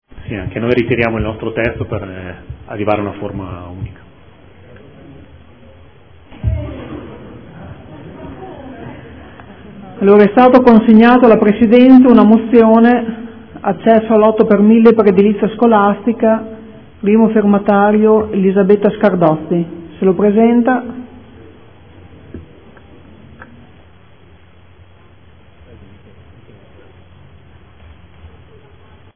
Seduta del 31/07/2014. Ritira odg perchè molto simile a quella presentata da M5S.